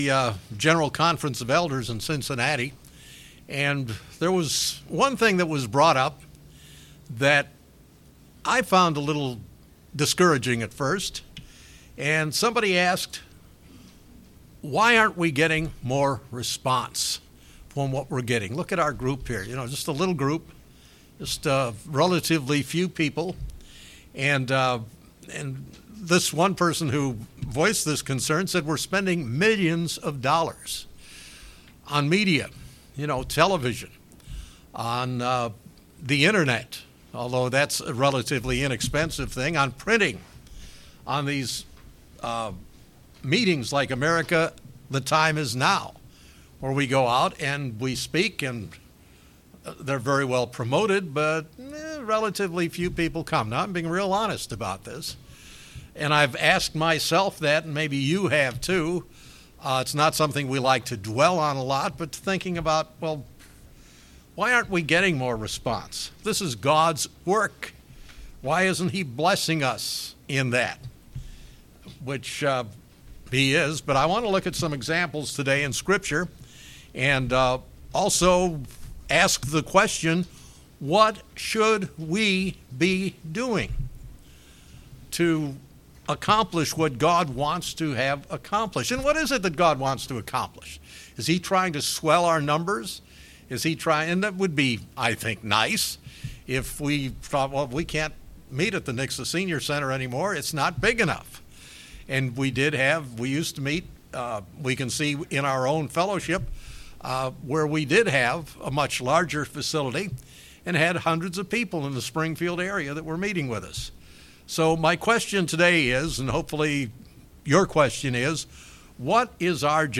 No matter how big or small a single congregation is, we have a job to do. In this sermon, the speaker looks into the subject of our Christian job.